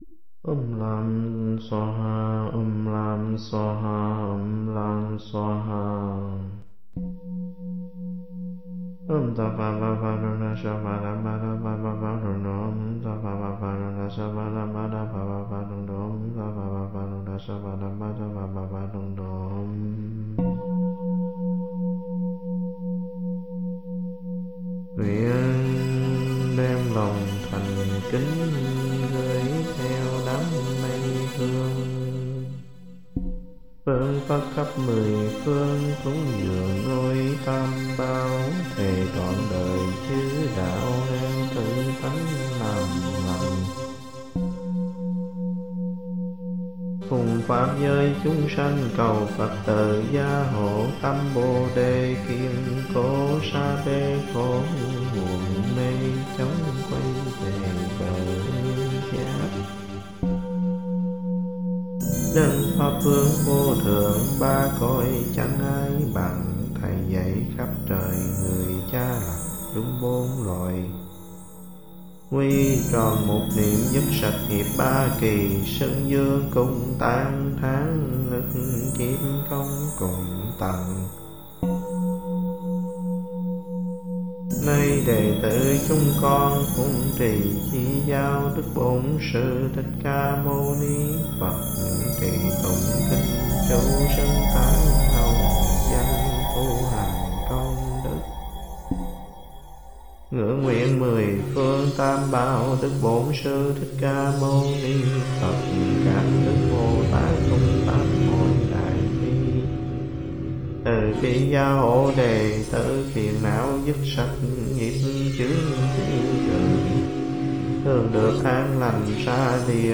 Soundscapes and images inspired (at least in part) by avant garde composers of the last century, including Karheinz Stockhausen and György Ligeti.
Having “rediscovered” the file gathering virtual dust, I had an idea to combine the otherworldly chant with wide, evolving synthesiser pads.
At the end of the piece, the metaphor of enlightenment is enacted as the human voice sonically mutates until – for a brief final phrase – the listener can no longer distinguish between voice and synthetic sound.